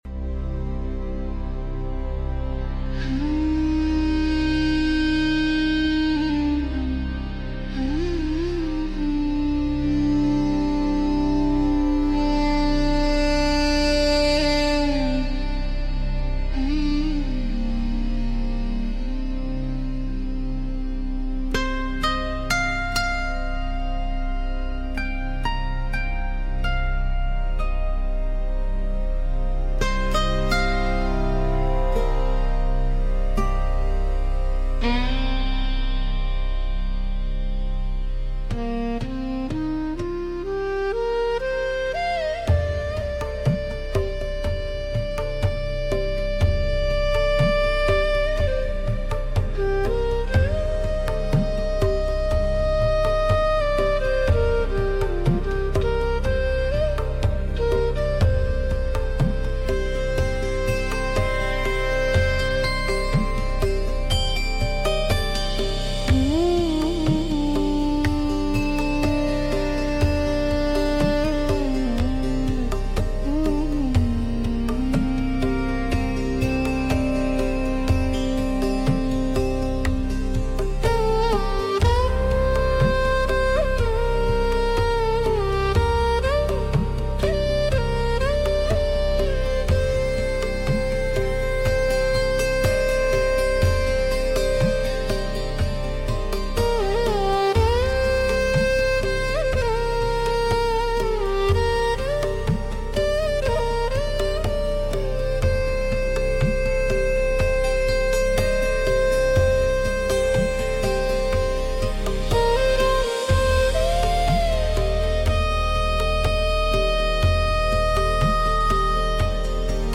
East-West fusion | Free-flow tempo | Sacred resonance